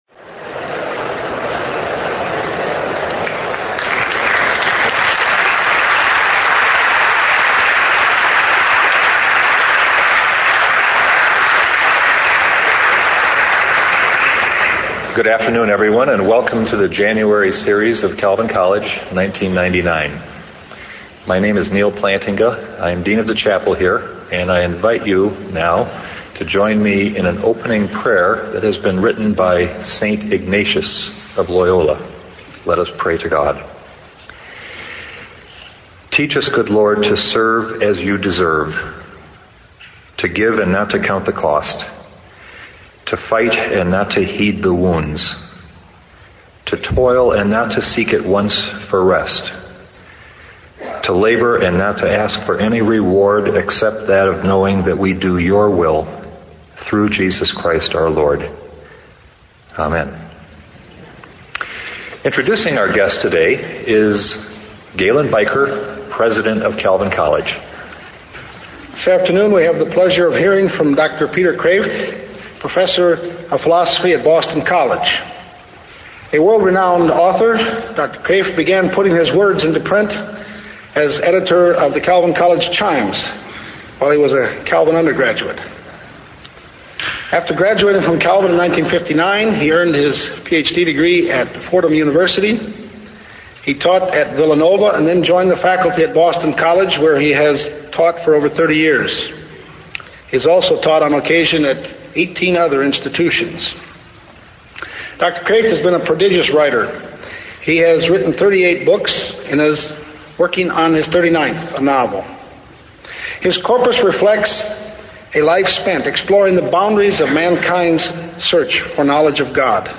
I found a recording of the lecture available for free on Dr. Kreeft’s webpage when I first learned about him.